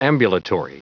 Prononciation du mot ambulatory en anglais (fichier audio)
Prononciation du mot : ambulatory